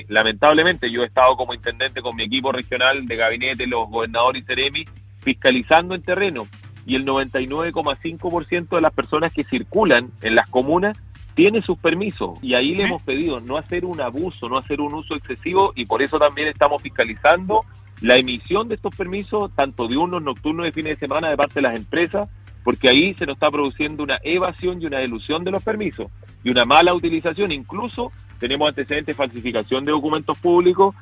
En conversación con Radio Sago, el Intendente de Los Ríos, César Asenjo, comentó la situación de su región y las estrategias de Testo, Trazabilidad y Aislamiento que pretenden implantar para mejorar los números de una región que hasta el momento tienen a todas sus comunas en cuarentena.